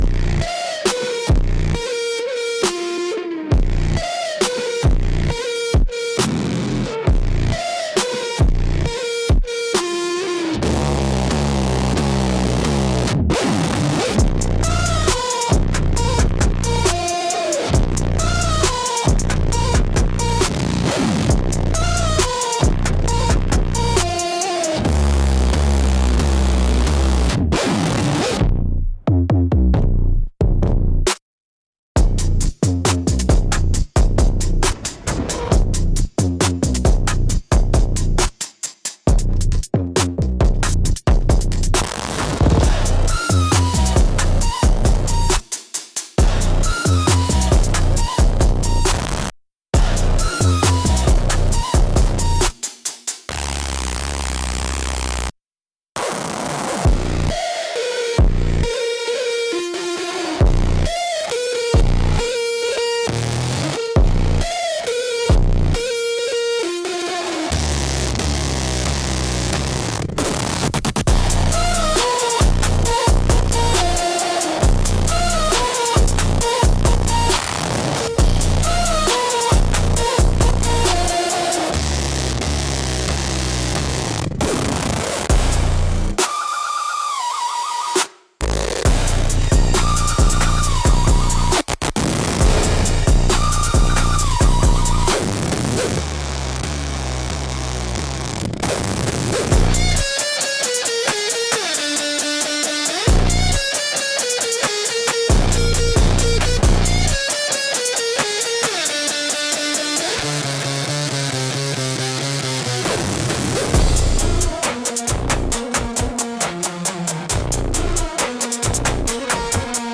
Instrumental - Southern Gothic x Rock Trap Blues